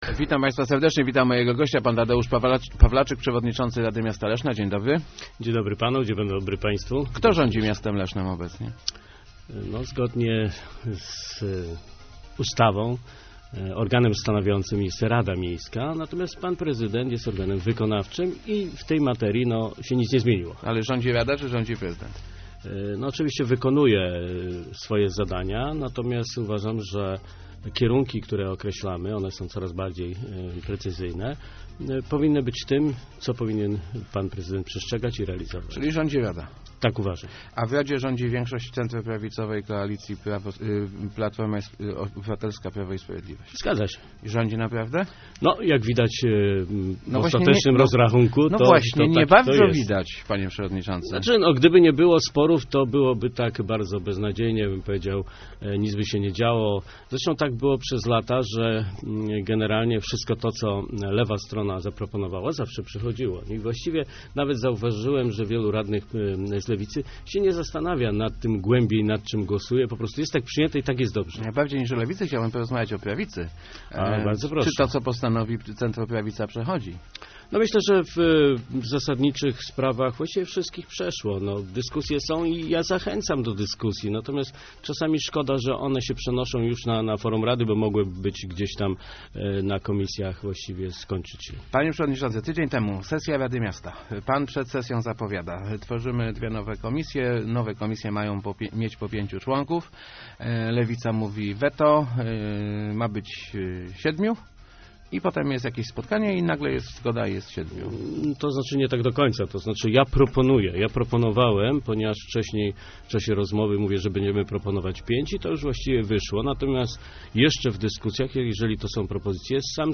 Nie jesteśmy zależni od PiS - mówił w Rozmowach Elki przewodniczący Rady Miasta Leszna Tadeusz Pawlaczyk z PO. Przyznaje, że w zarówno w klubie, jak i centroprawicowej koalicji dochodzi do różnic zdań, jednak jego zdaniem jedność nie jest zagrożona.